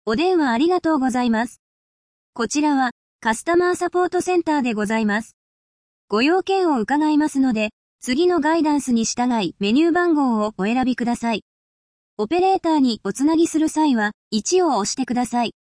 AI音声合成・音声読み上げ（WEB テキスト）ソフトのReadSpeaker（リードスピーカー）